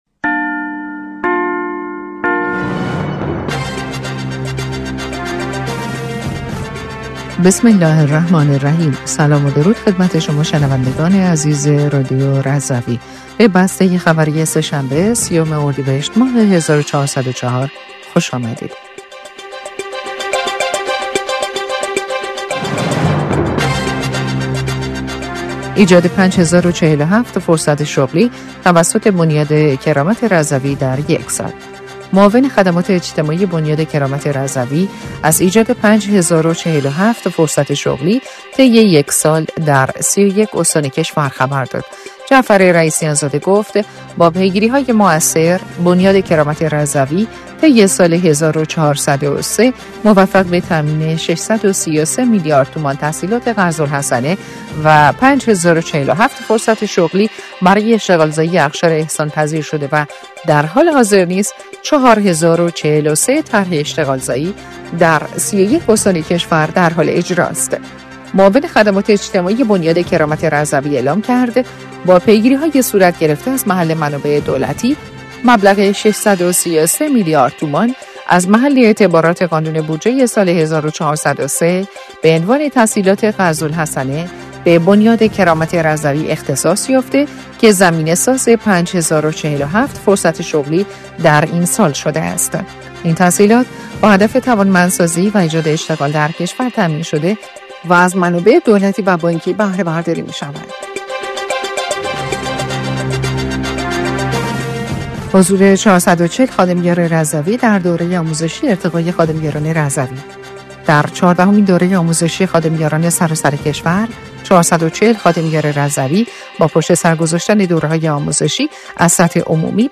بسته خبری ۳۰ اردیبهشت‌ماه رادیو رضوی/